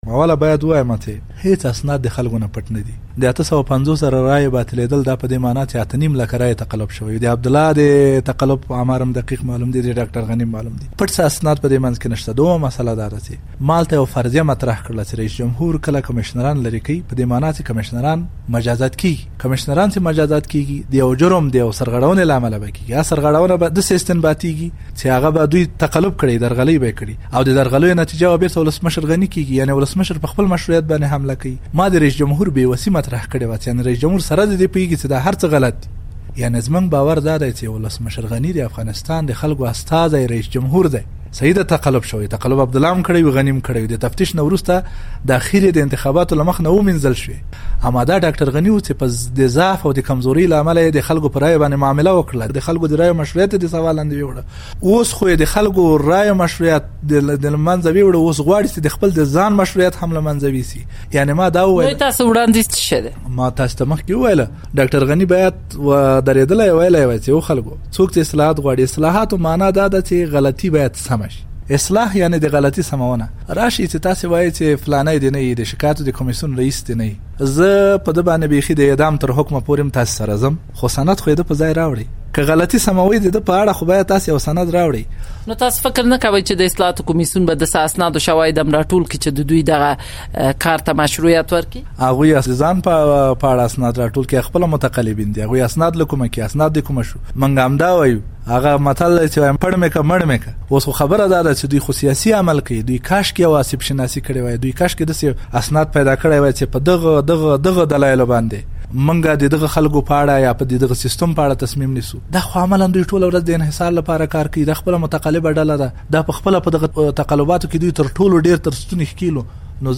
له ستار سعادت سره مرکه